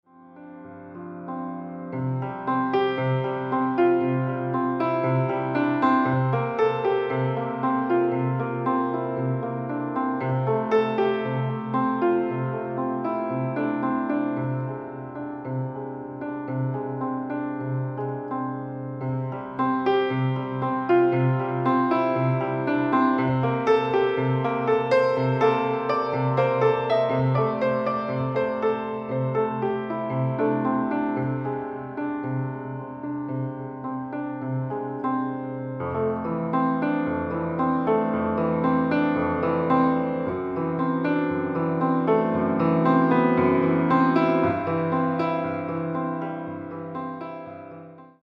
Piano solo "smooth".